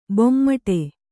♪ bommaṭe